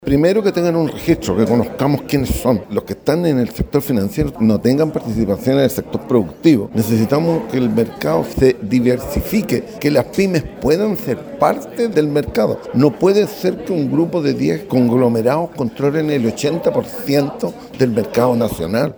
Se trató de una de las audiencias más esperadas del año, donde participaron abogados representantes de los grandes grupos económicos que operan en el país.
audiencia-conadecus-mat.mp3